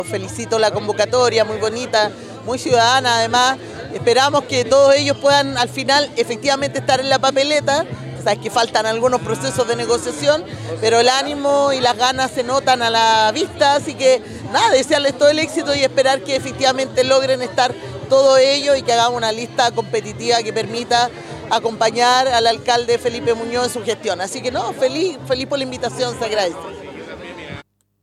La proclamación se realizó en la Plaza del Cristo, durante la mañana de este sábado ante una gran asistencia de adherentes